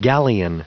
Prononciation du mot galleon en anglais (fichier audio)
Prononciation du mot : galleon